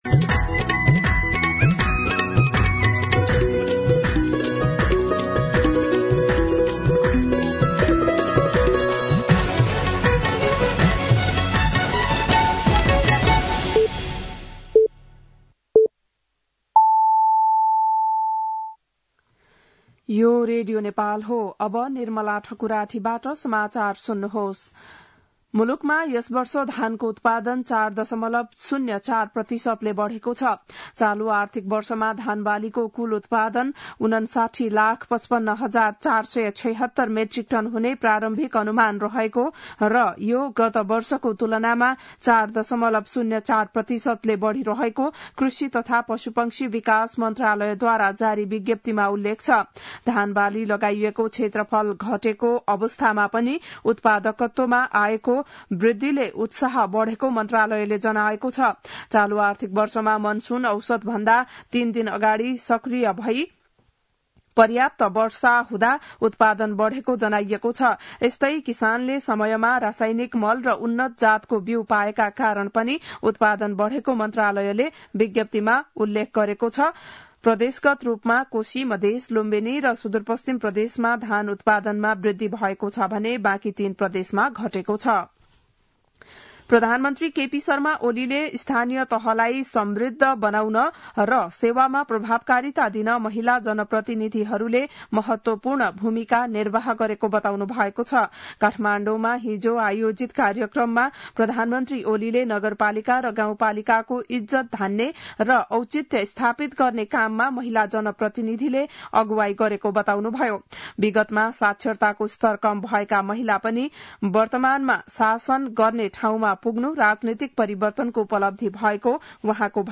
बिहान ११ बजेको नेपाली समाचार : २१ पुष , २०८१
11-am-Nepali-News.mp3